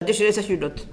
Localisation Saint-Hilaire-des-Loges
Catégorie Locution